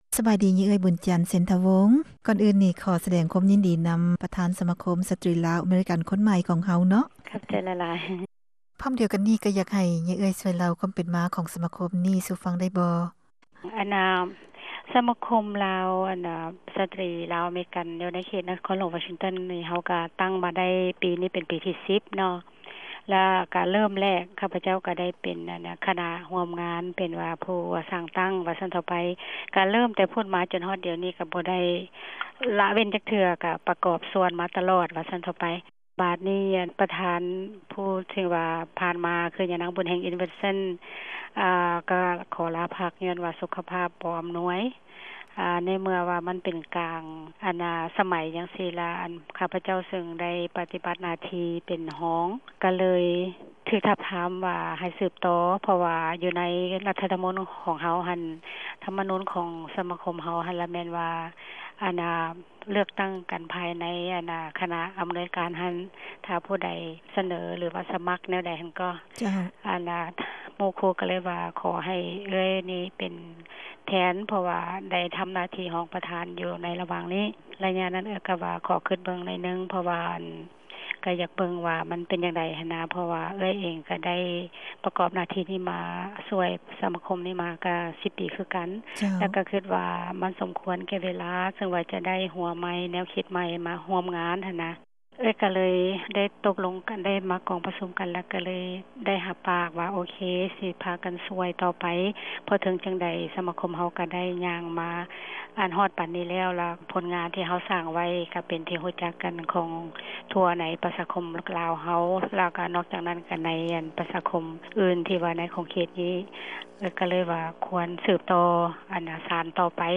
ສຳພາດ